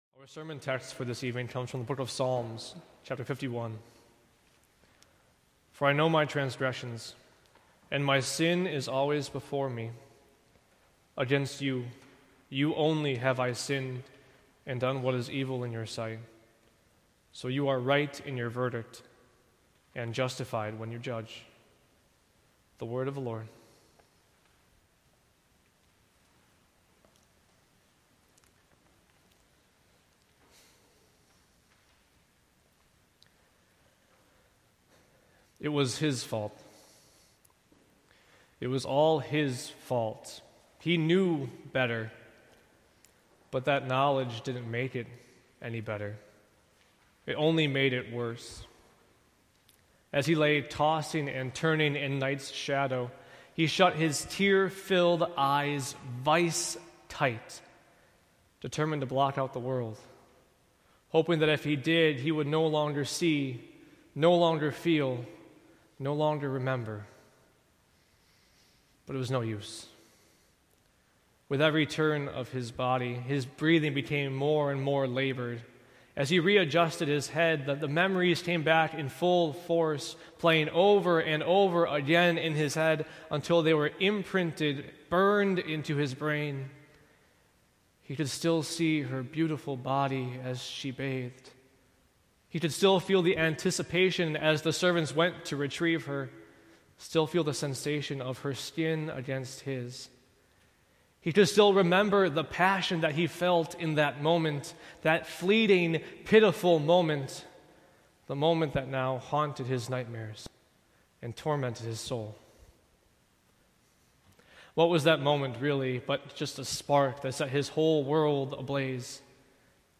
Sermons from Faith Evangelical Lutheran Church (WELS) in Antioch, IL